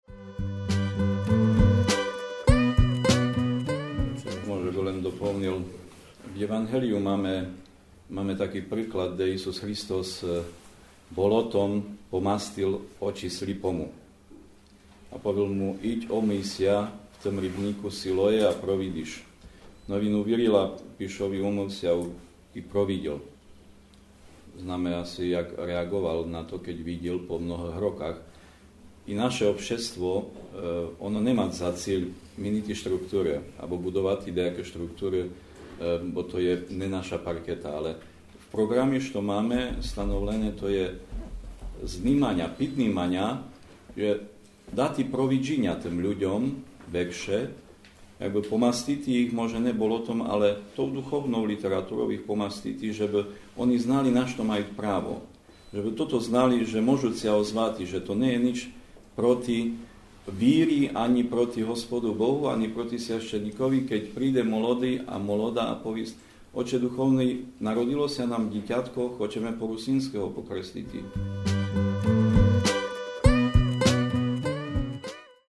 одбыла ся 25. мая 2015 рока в Піддукляньскій Бібліотеці во Свиднику в рамках 3. Русиньского Фестівалю